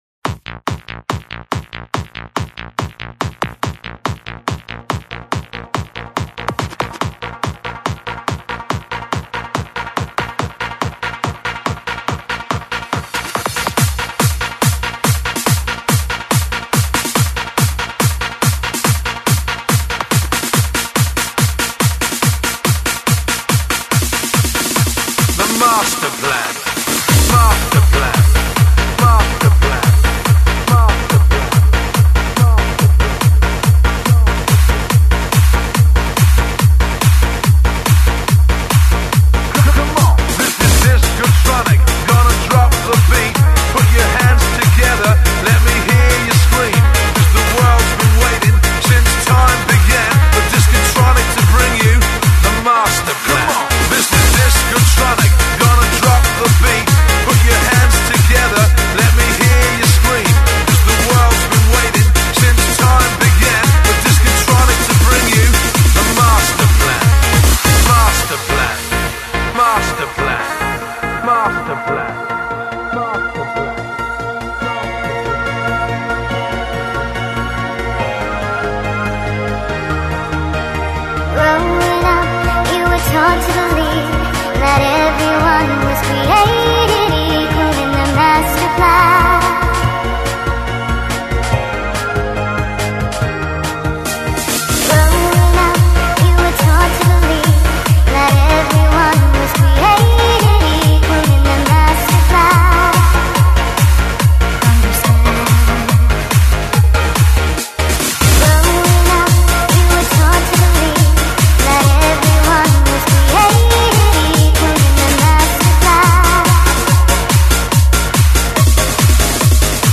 Жанр:Techno